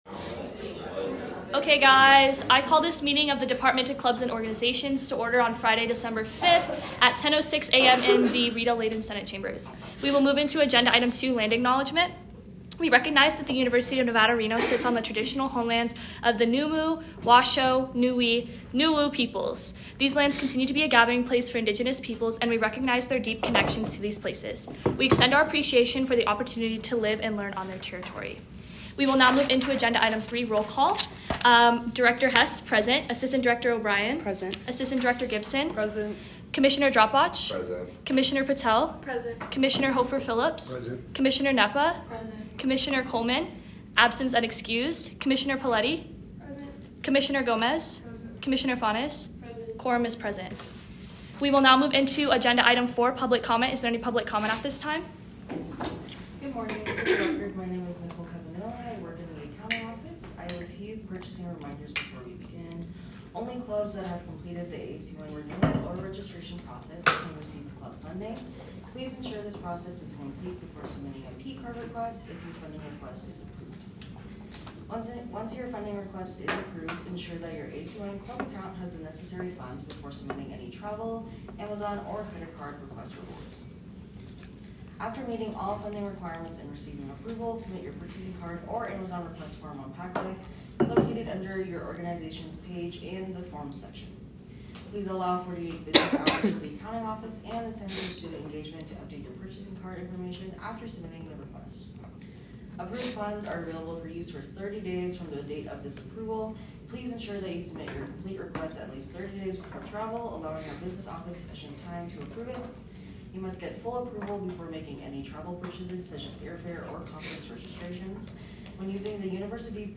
Location : Rita Laden Senate Chambers - located on the third floor of the JCSU
Audio Minutes